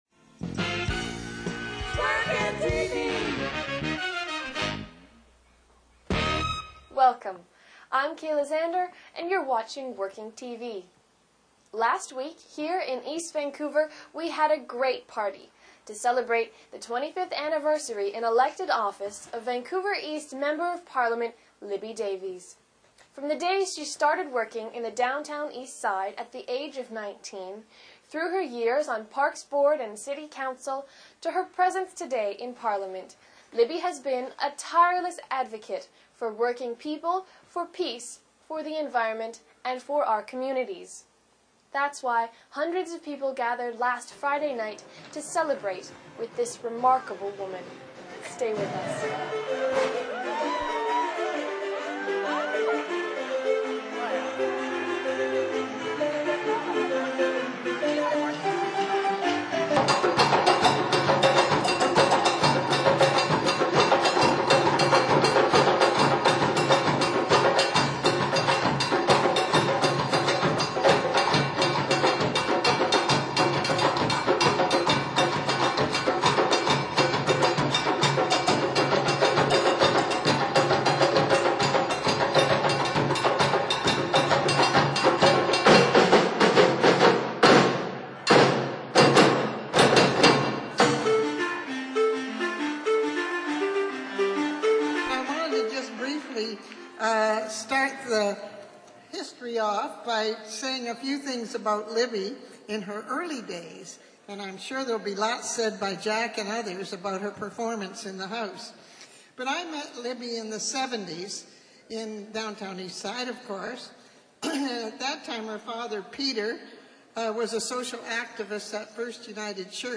Webcast highlights from the May 12, 2006 party for Libby Davies, the NDP Member of Parliament for Vancouver East, to celebrate her 25 years in public service.
Shaw TV 4 broadcast
Introduction - Margaret Mitchell, Van East NDP MP 1972 -93